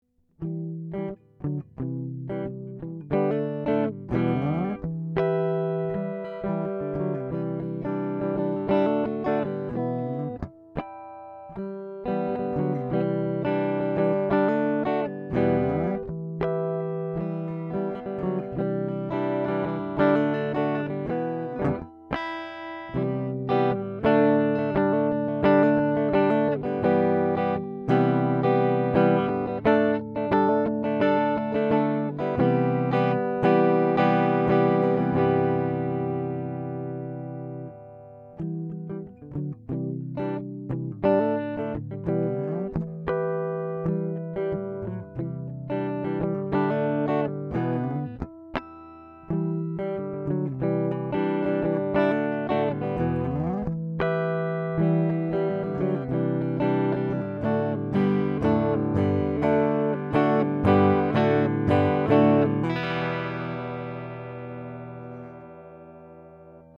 Up for consideration is a 2009 Gibson CS-336 electric guitar in near-mint condition, with original hang tags and hardshell case.
Here are 8 quick, 1-take MP3s of this guitar, tracked using a Schoeps CMT 541 microphone on Peavey Amp with a Behringer Virtualizer effects unit into a Sony PCM D1 flash recorder. This is straight, pure signal with no additional EQ or effects.
It has fantastic sustain due to the all-solid mahogany back, neck, and center block design.